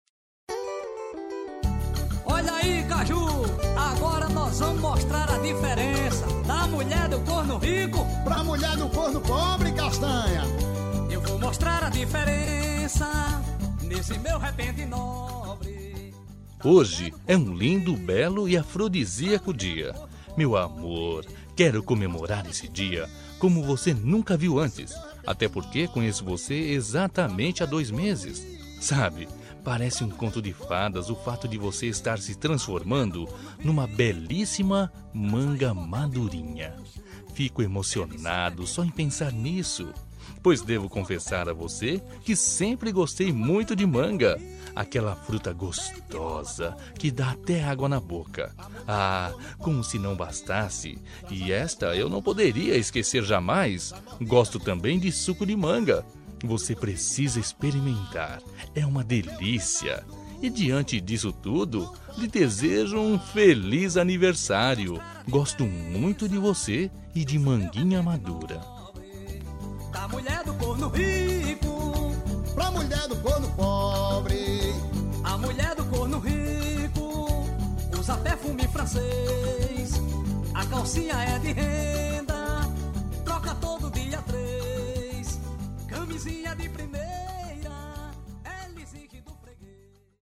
Aniversário de Humor – Voz Masculina – Cód: 200213